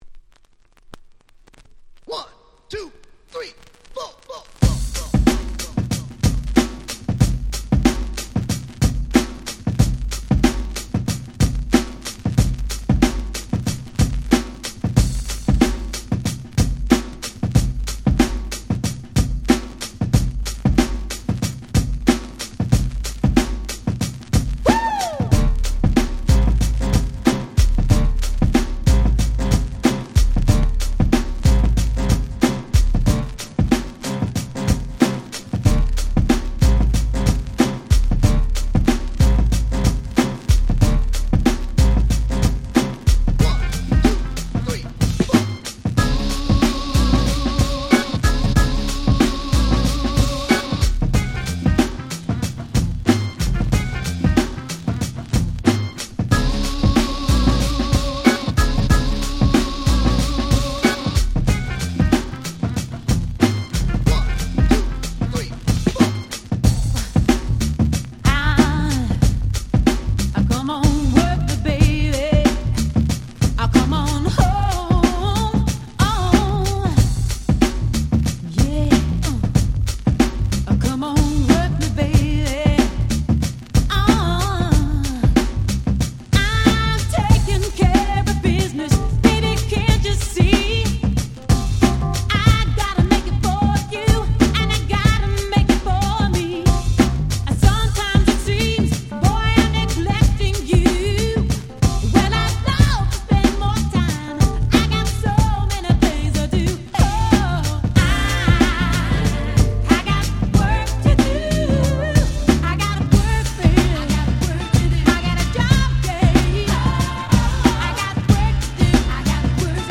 92' Nice Remix !!